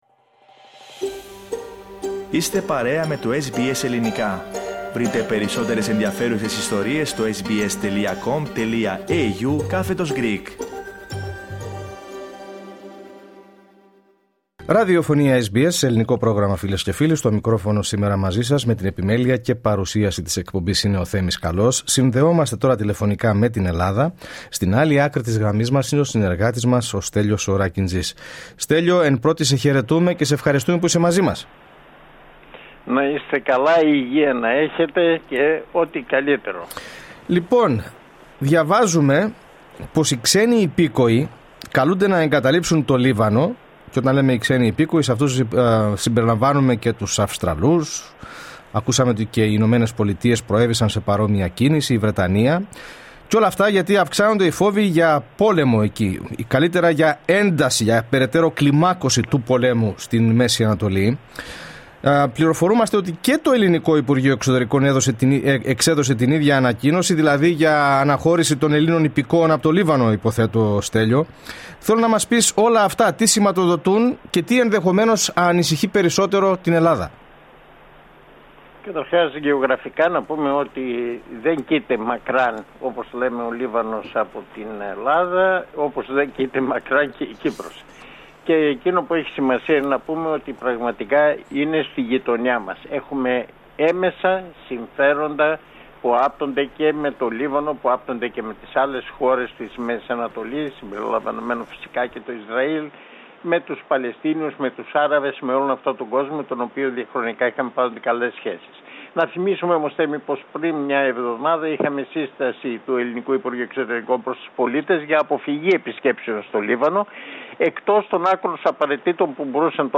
Ακούστε την εβδομαδιαία ανταπόκριση από την Αθήνα